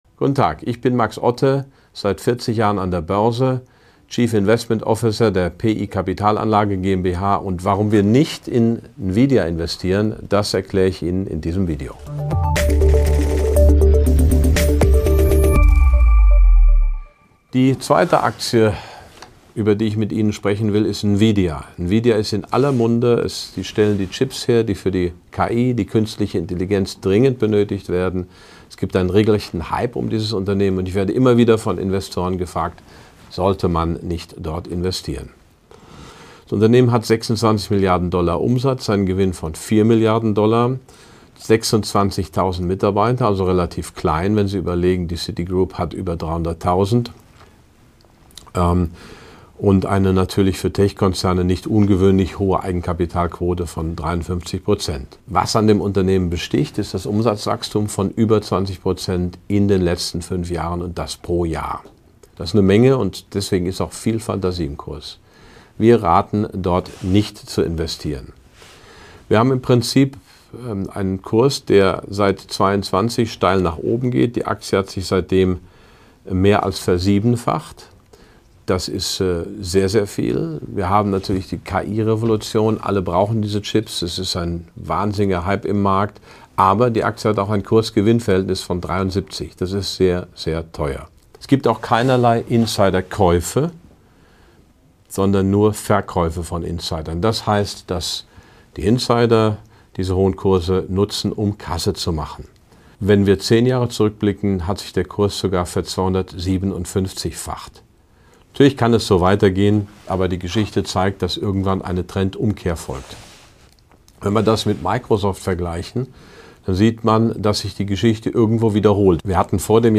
Der Vortrag bietet fundierte Einblicke in Ottes